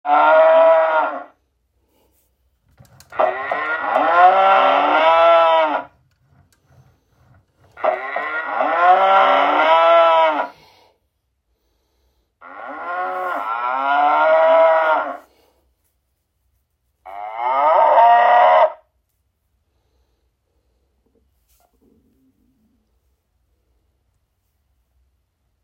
Cows QSI.m4a